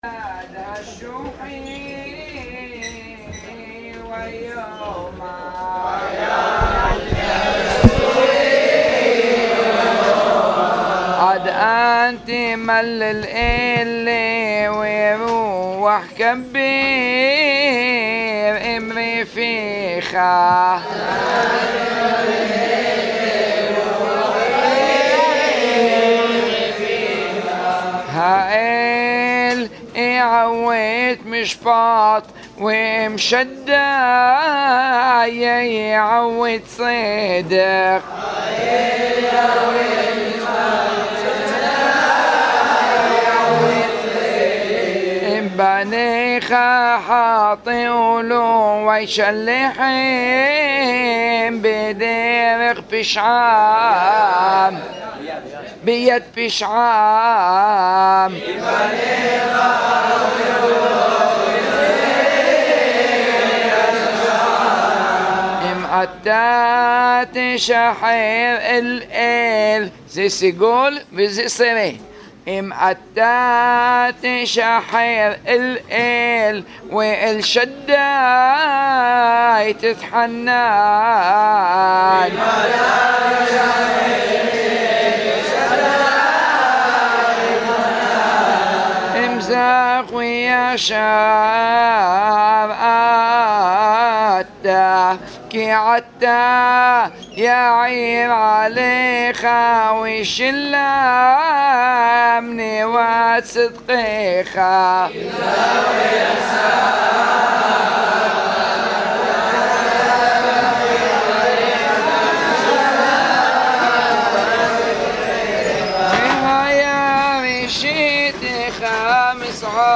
שעור נפלא בקריאת פסוקי ספר איוב והבנת המסרים